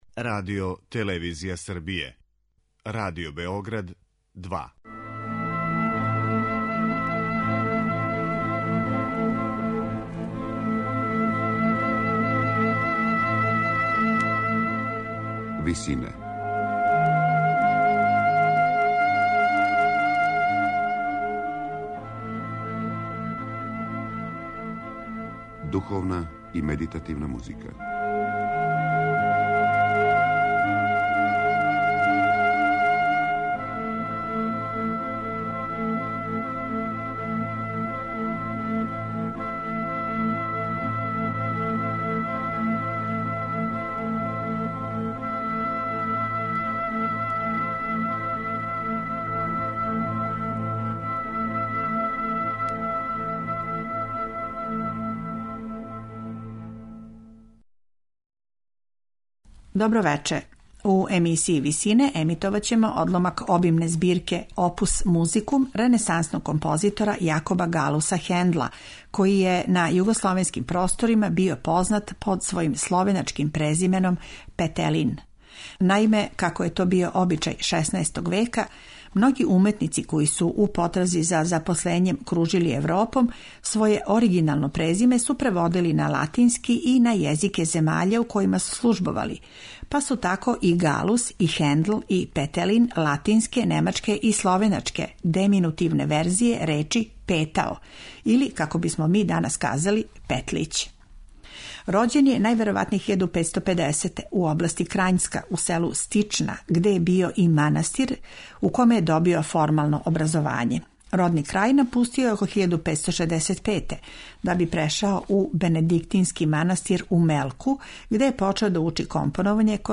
у ВИСИНАМА представљамо медитативне и духовне композиције аутора свих конфесија и епоха.
Пева их вокални ансамбл „Хуелгас", којим руководи Паул ван Невел.